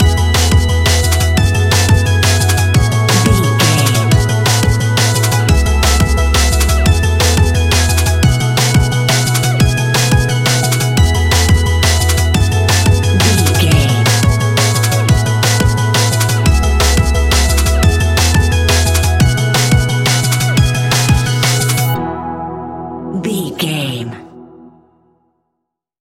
Ionian/Major
electronic
dance
techno
trance
synths
instrumentals